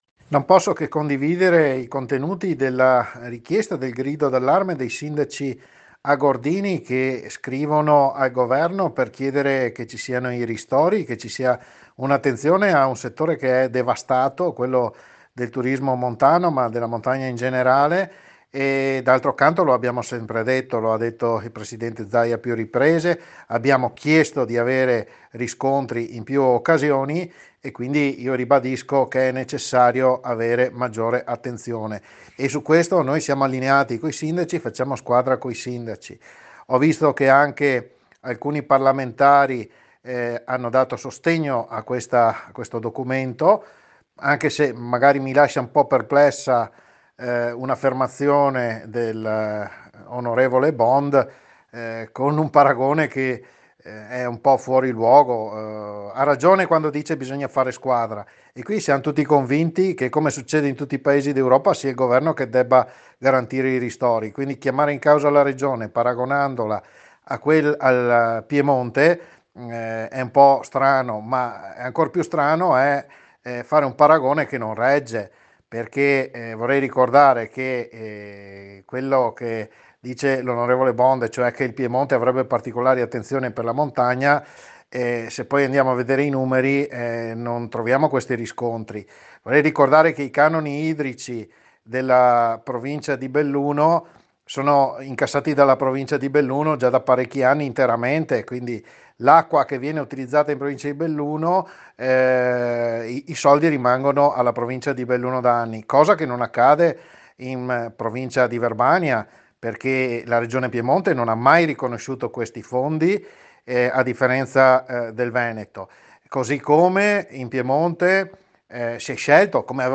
BELLUNO L’assessore regionale Gianpaolo Bottacin interviene sul caso del giorno nell’Agordino. Un grido di allarme in seguito alla crisi economica e sociale causato dall’epidemia covid 19.